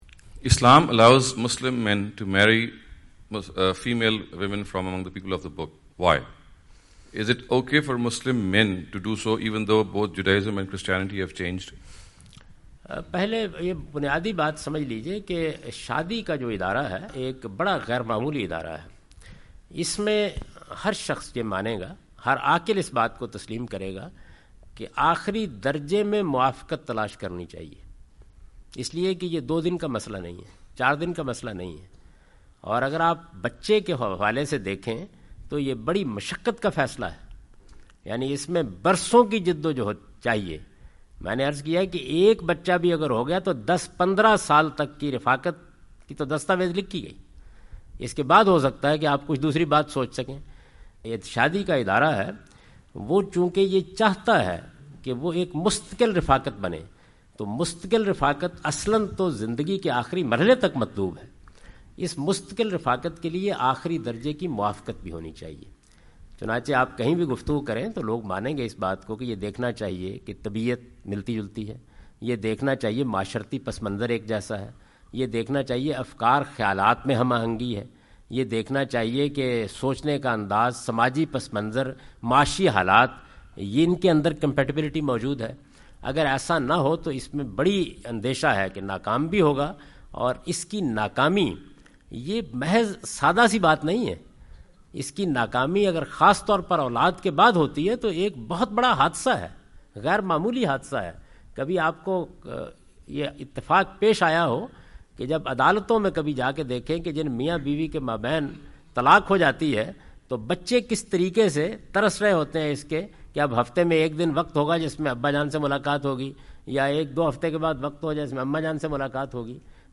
Javed Ahmad Ghamidi answer the question about "Interfaith Marriages in Islam" during his US visit.
جاوید احمد غامدی اپنے دورہ امریکہ کے دوران ڈیلس۔ ٹیکساس میں "اہل کتاب سے نکاح" سے متعلق ایک سوال کا جواب دے رہے ہیں۔